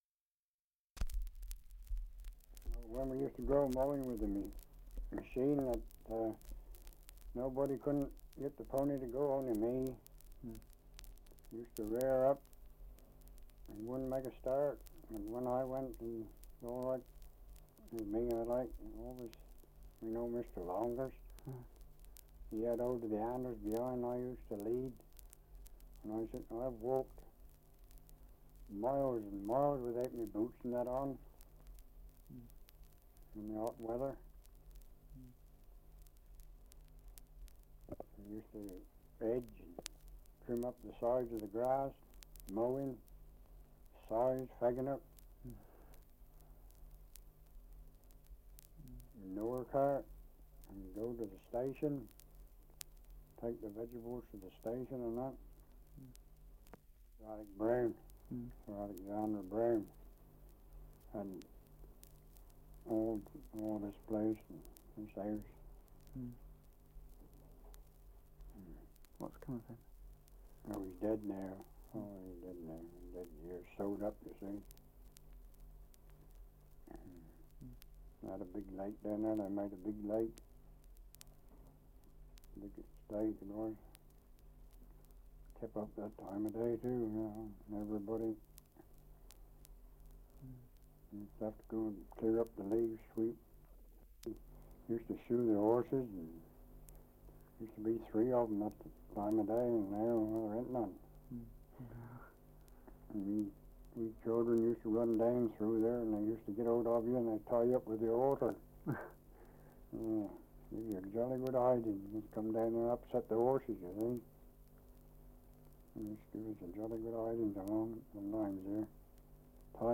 Survey of English Dialects recording in Coldharbour, Surrey
78 r.p.m., cellulose nitrate on aluminium